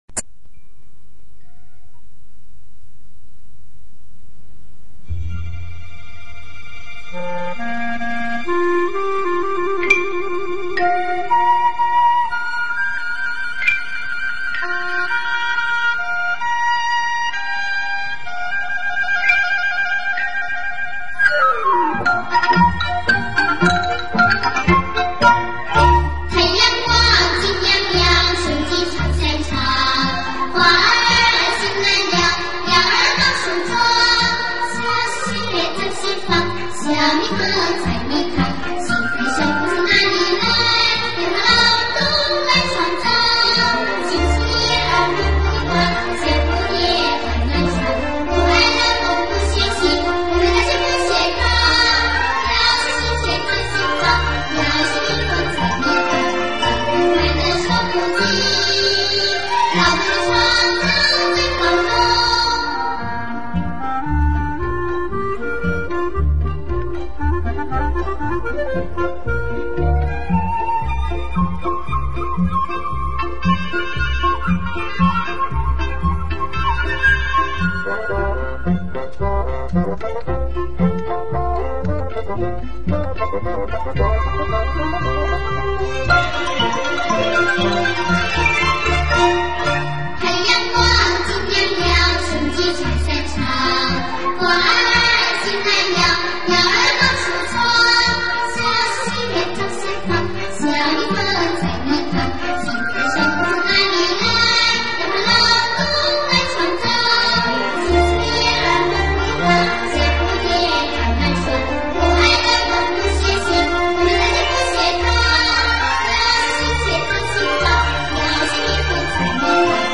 儿童歌曲MTV精选《劳动最光荣》_(new).mp3